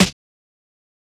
Snares
BattleCatSnare4.wav